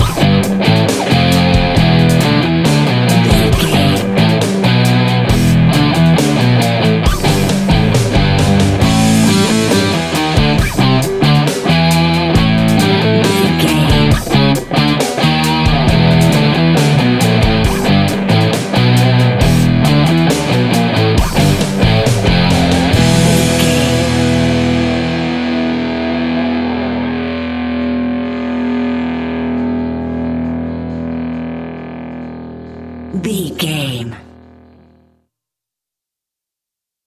Epic / Action
Aeolian/Minor
hard rock
heavy metal
dirty rock
Heavy Metal Guitars
Metal Drums
Heavy Bass Guitars